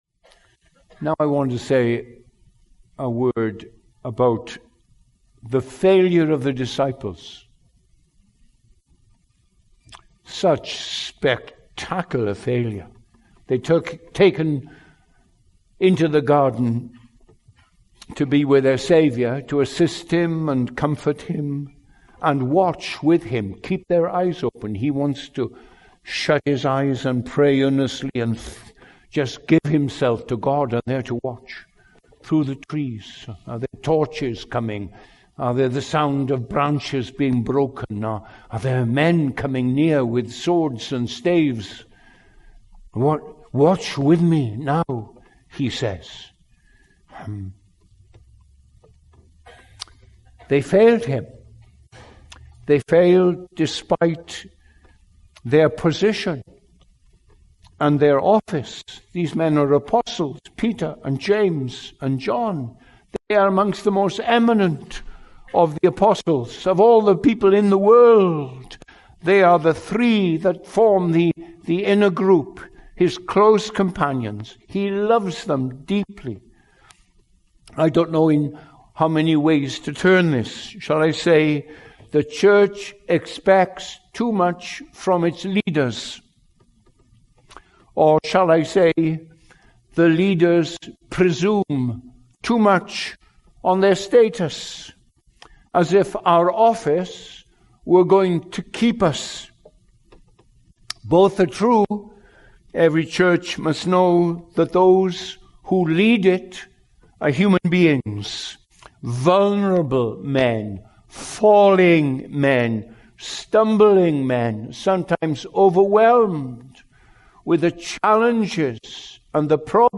This excerpt was taken from the full sermon, “ The Praying Christ and His Humanity “.